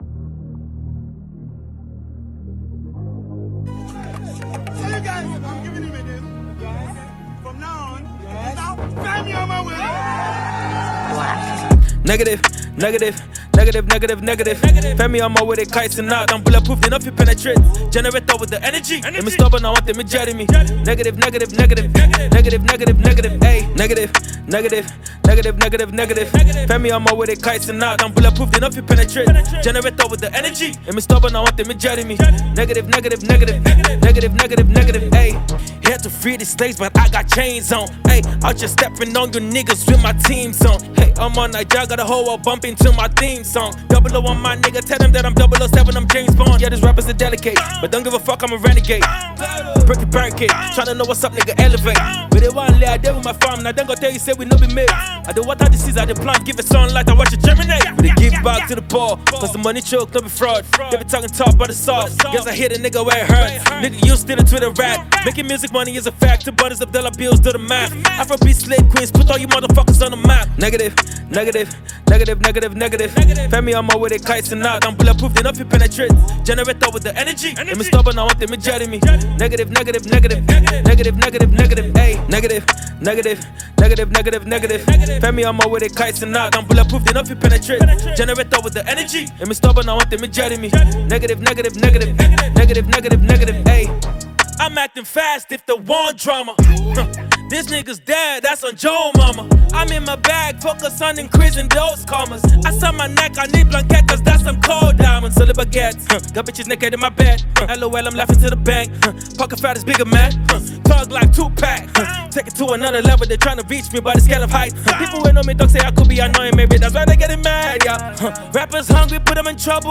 Noteworthy Nigerian rap guru and lyricist